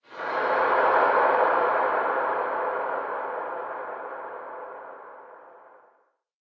cave4.ogg